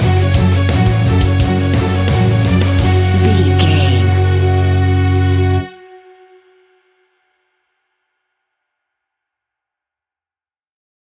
royalty free music
Epic / Action
Fast paced
Aeolian/Minor
F#
hard rock
scary rock
Heavy Metal Guitars
Metal Drums
Heavy Bass Guitars